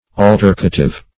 Search Result for " altercative" : The Collaborative International Dictionary of English v.0.48: Altercative \Al"ter*ca*tive\, a. Characterized by wrangling; scolding.